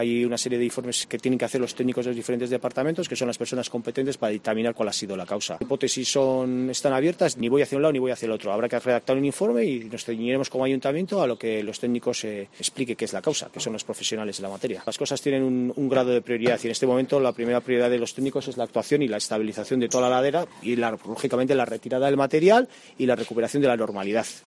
Martín Ibabe, edil de Seguridad Ciudadana.